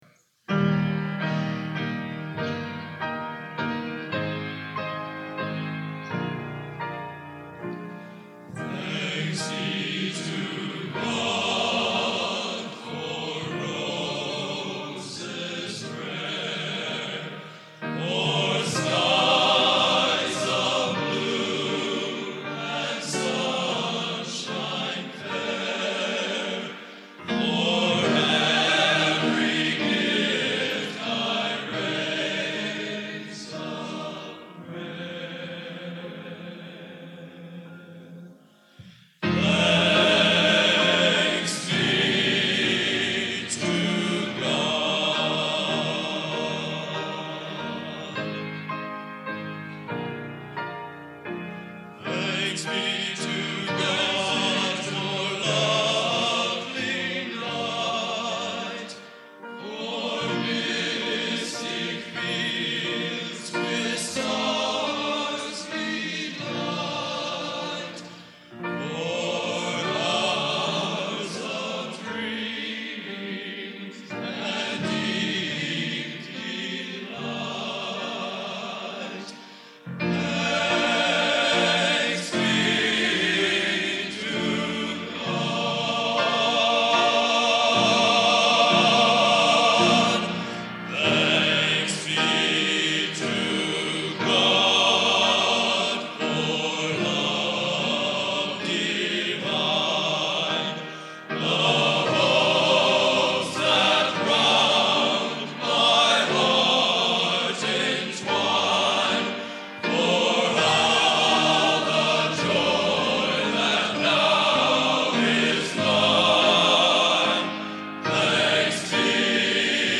Genre: Modern Sacred | Type: